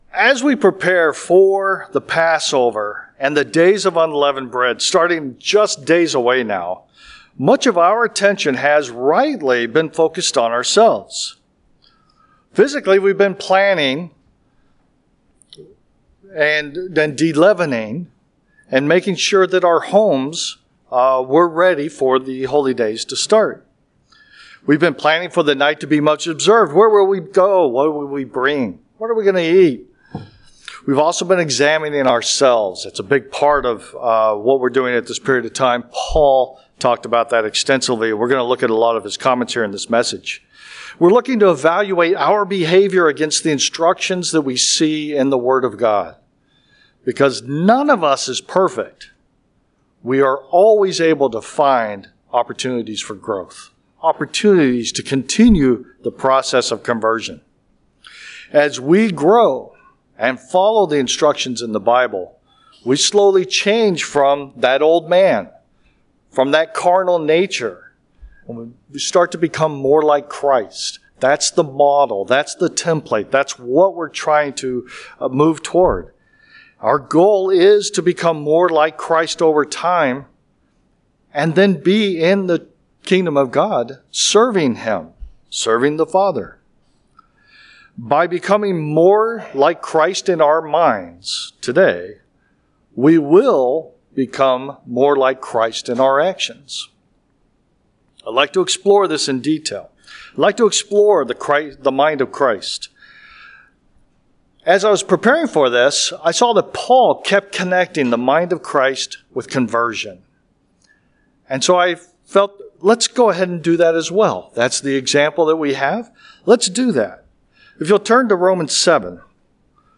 Given in Chicago, IL Northwest Indiana